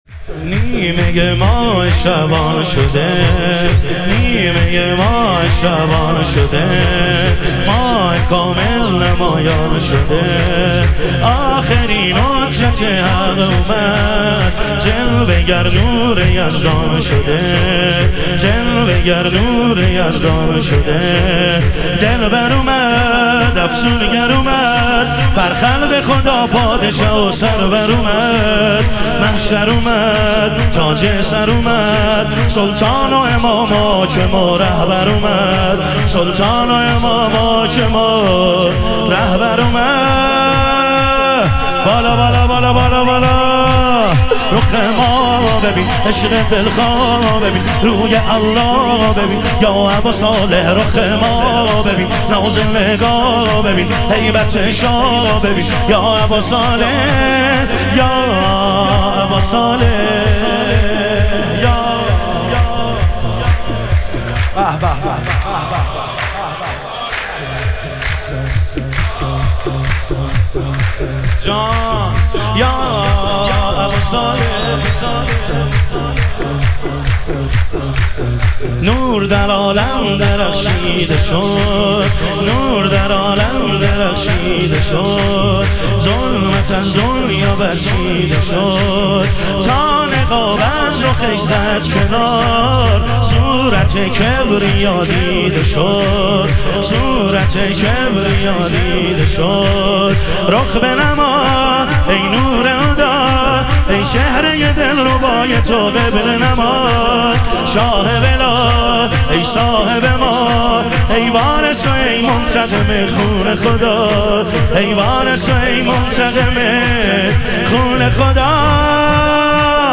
شور
شب ظهور وجود مقدس حضرت مهدی علیه السلام